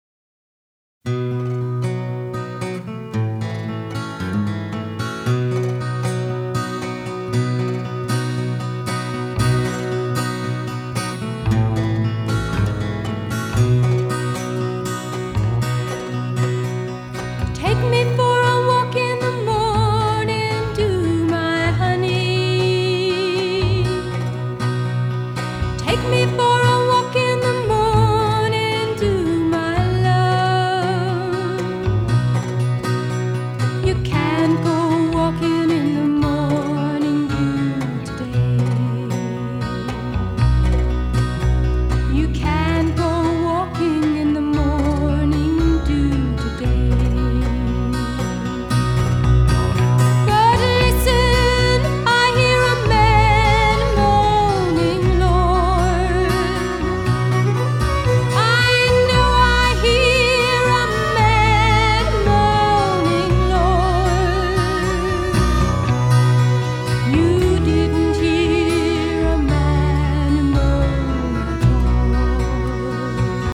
發燒女聲、發燒天碟